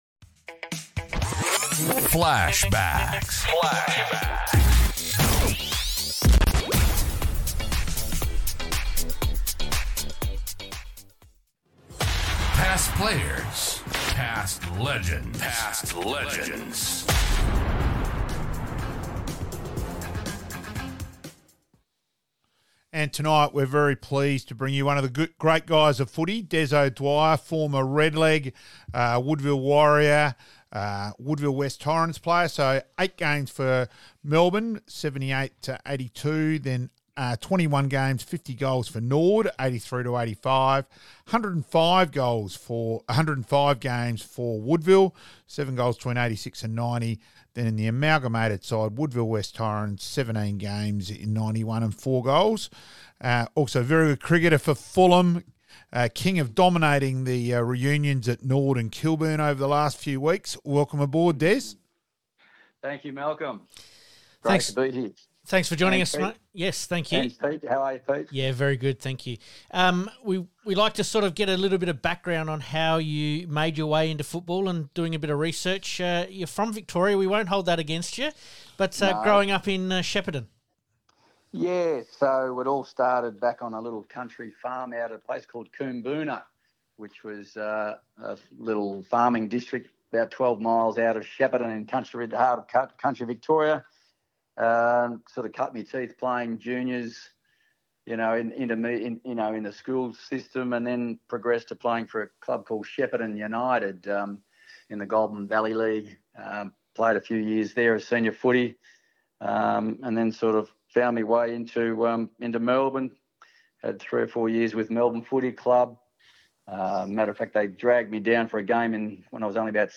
FLASHBACKS - Re-Live some of our Interviews (only) with some of our special guests